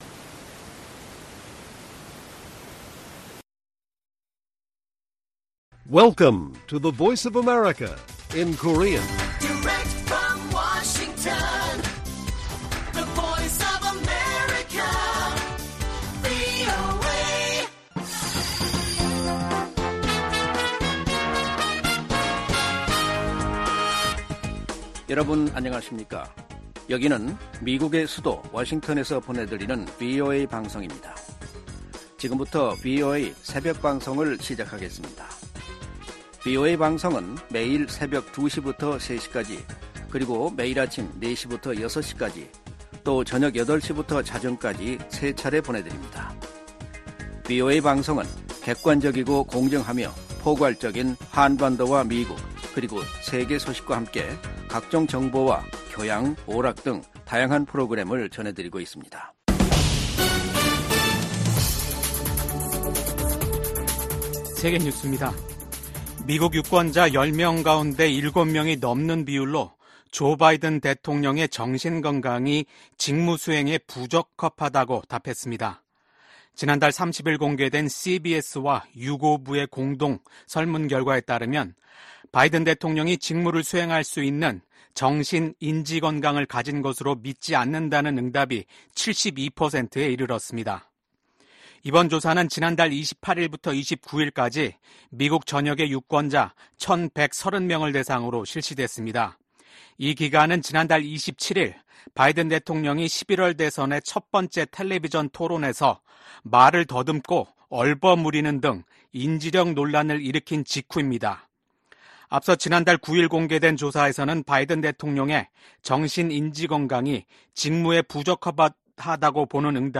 VOA 한국어 '출발 뉴스 쇼', 2024년 7월 2일 방송입니다. 북한이 한반도 시각 1일 탄도미사일 2발을 발사했다고 한국 합동참모본부가 밝혔습니다. 유엔 안보리가 공식 회의를 열고 북한과 러시아 간 무기 거래 문제를 논의했습니다. 북러 무기 거래 정황을 노출했던 라진항에서 또다시 대형 선박이 발견됐습니다.